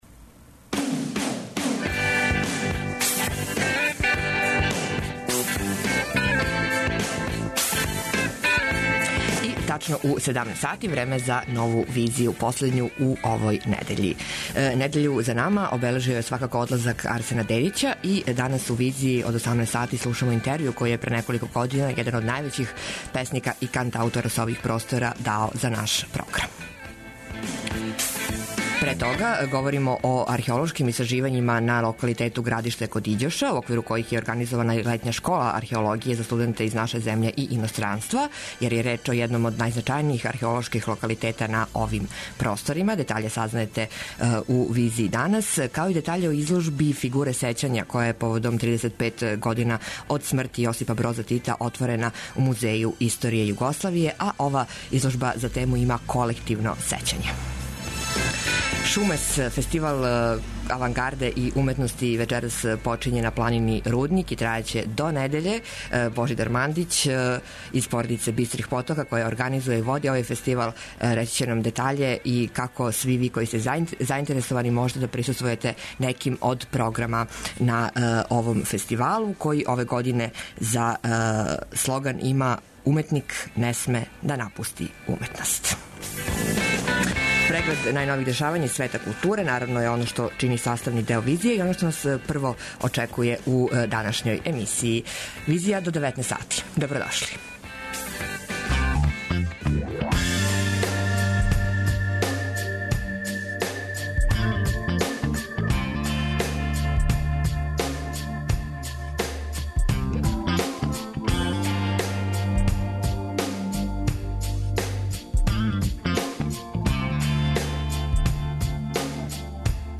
У данашњој Визији слушамо интревју који је пре неколико година један од највећих песника и кантаутора са ових простора, дао за наш програм. Говоримо о археолошким истраживањима на локалитету Градиште код Иђоша, у оквиру којих је организована и Летња школа археологије за студенте из наше земље и иностранства, јер је реч о једном од најзначајнијих археолошких локалитета на овим просторима. Чућете и причу о изложби “Фигуре сећања” која је поводом 35 година од смрти Јосипа Броза Тита отворена у Музеју историје Југославије.